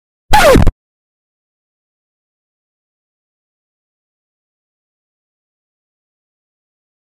На этой странице собраны звуки магнитофона: шум ленты, щелчки кнопок, запись с кассет.
Кассету заклинило